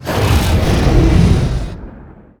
CosmicRageSounds / wav / general / combat / creatures / dragon / he / turn1.wav